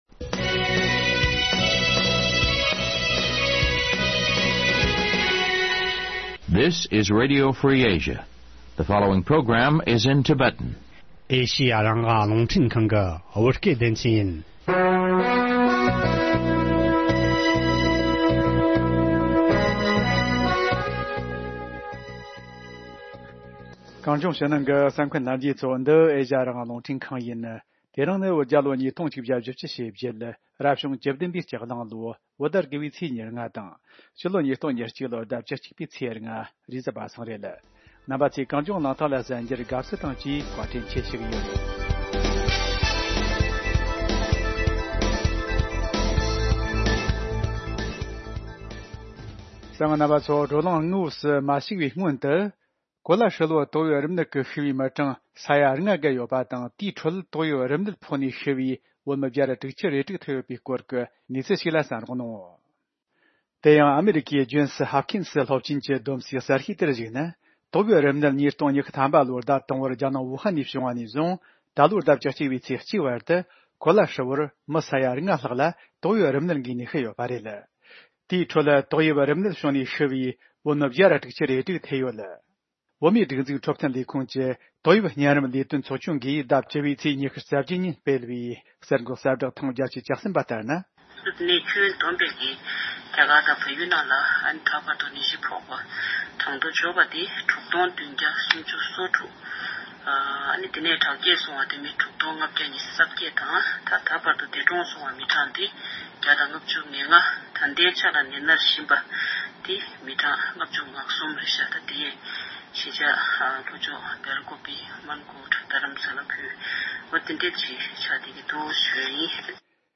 བཅར་འདྲི་བྱས་པའི་ལས་རིམ།